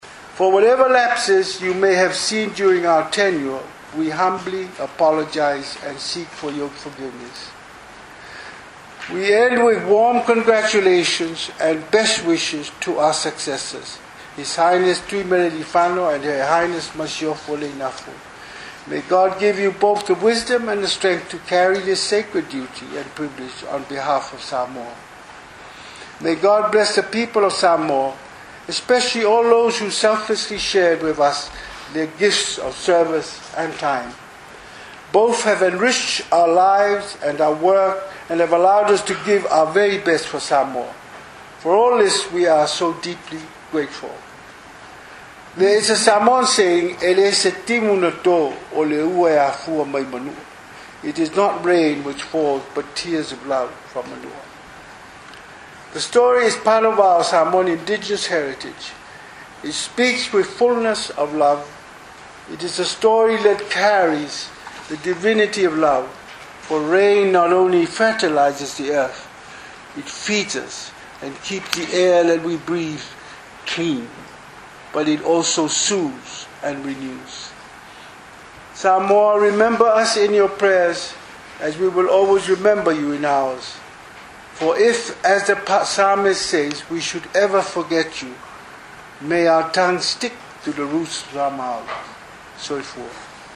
Samoans throughout the world are reacting with emotion to the farewell speech by Samoa’s Head of State Tuiatua Tupua Tamasese Efi which was broadcast on TV3 last night.
Tuiatua who keynoted American Samoa’s 2017 Flag Day in April spoke as a father, giving advice, thank yous and parting words.